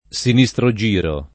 sinistrogiro [ S ini S tro J& ro ] agg.